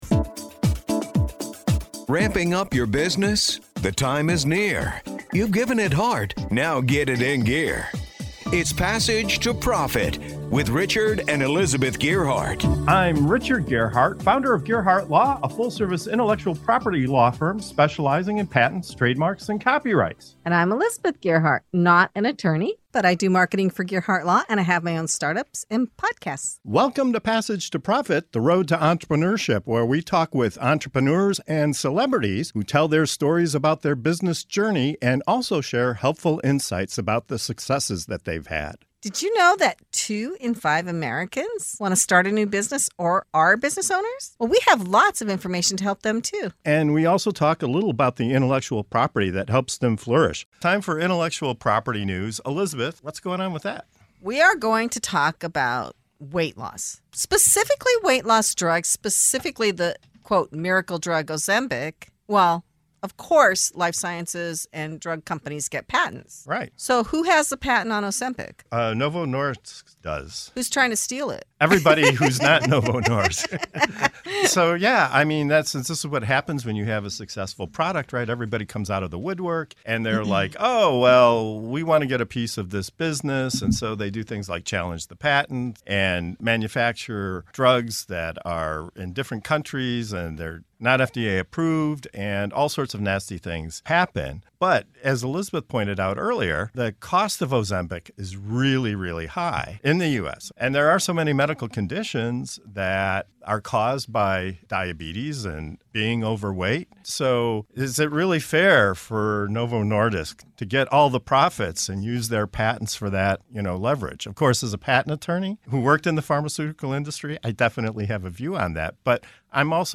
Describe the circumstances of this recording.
In this segment of “IP in the News” on the Passage to Profit Show we dive into the heated debate over Ozempic, the so-called "miracle" weight loss drug, and the patent battle that could change access to it.